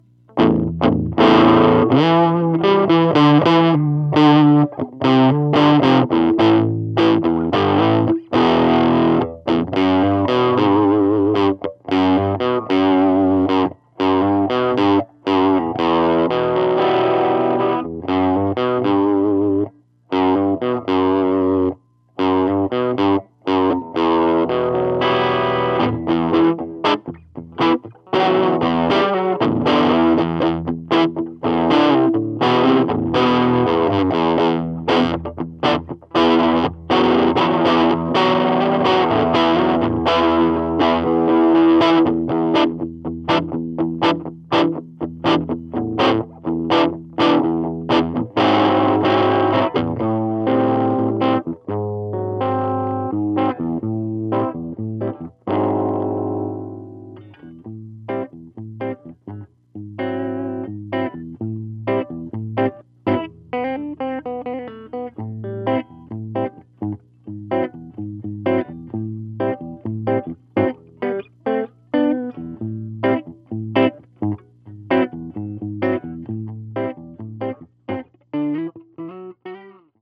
These were taken straight from mic-to-recording, with no processing.
High Input Fuzz Drive.... then roll back the guitar volume